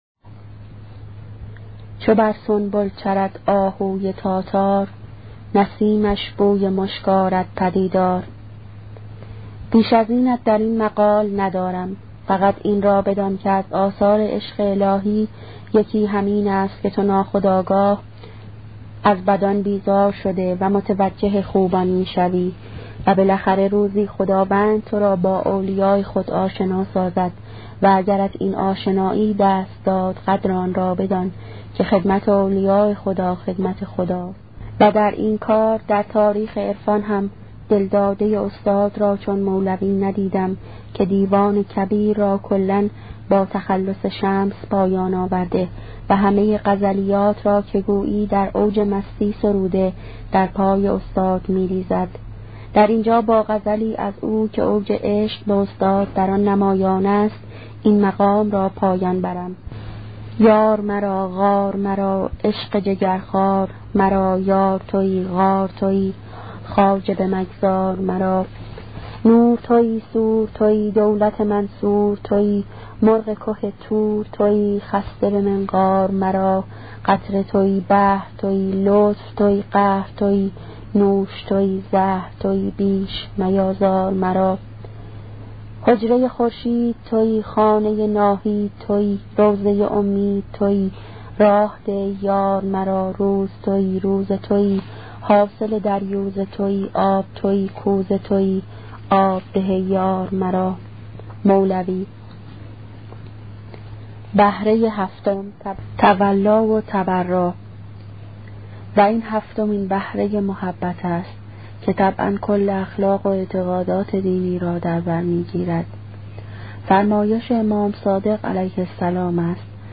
کتاب صوتی عبادت عاشقانه , قسمت یازدهم